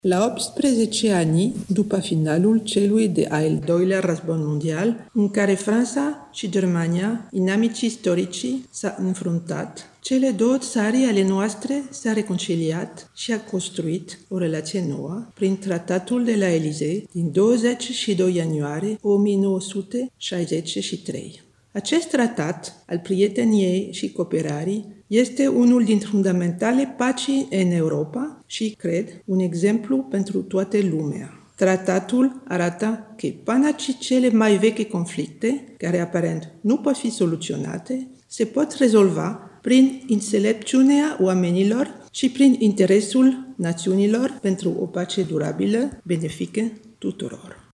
Excelența Sa Michèle Ramis, Ambasadorul Franței la București:
Ambasadorul-Frantei-Michele-Ramis-1.mp3